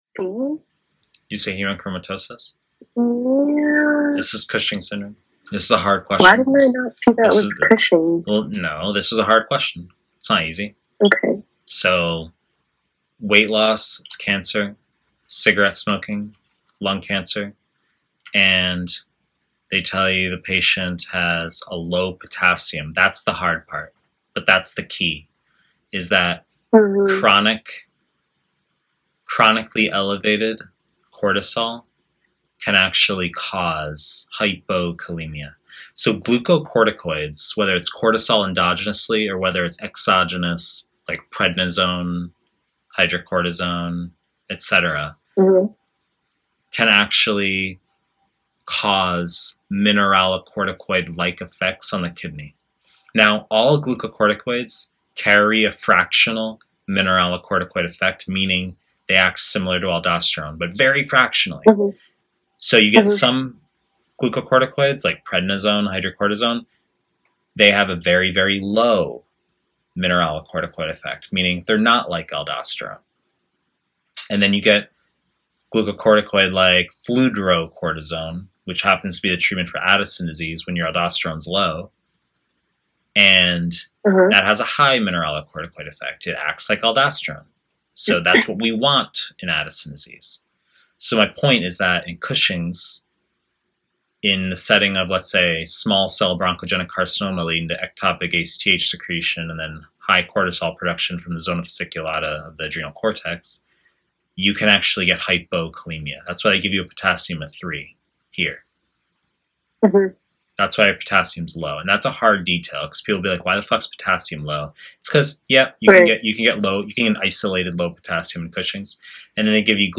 Internal Medicine / Pre-recorded lectures